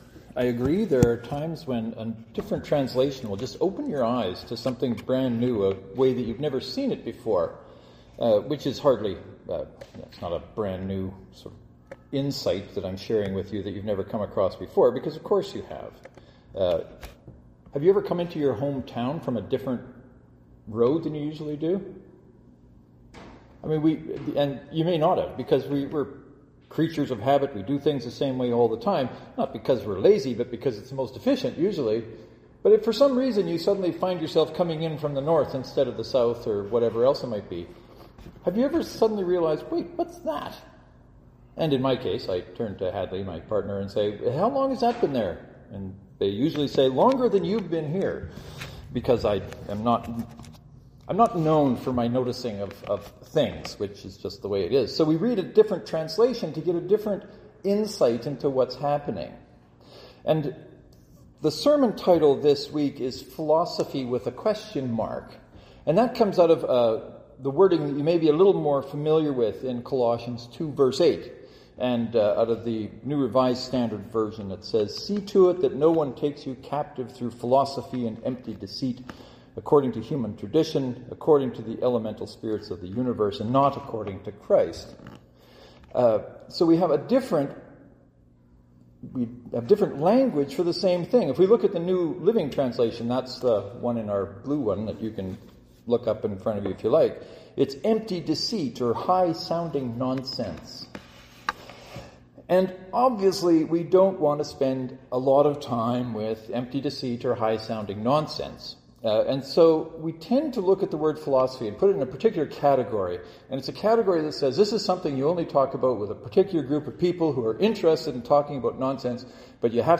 The sermon is helping us see where we might be too narrow in our definitions and usages of “philosophy,” how we might have misused this text to miss some critical lessons from outside our own religious circles, and a better way to look at the whole world.